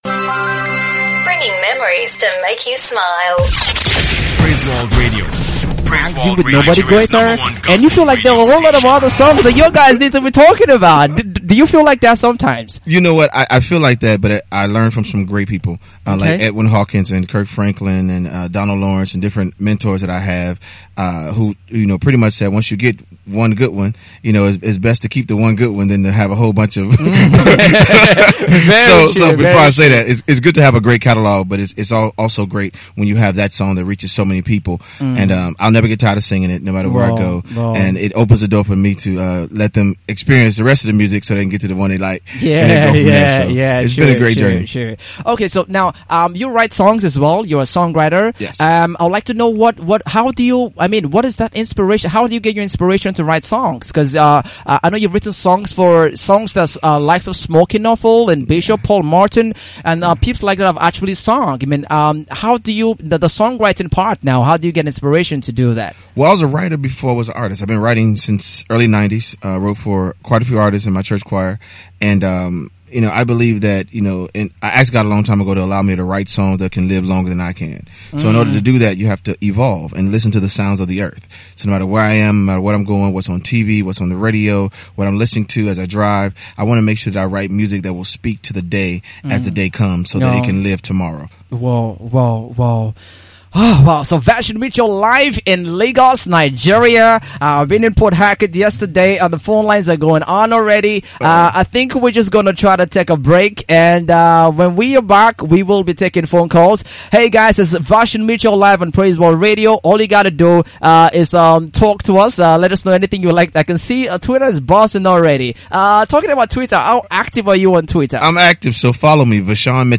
Six time stellar award winner and Grammy Nominee VaShawn Mitchell was LIVE at the Praiseworld Radio studios on Monday, December 9 for an exclusive interview. The singer and songwriter shared profound insights on his music, his personal life, his ministry and his forthcoming album “Unstoppable”.
Several excited callers also called in to show the American singer some love.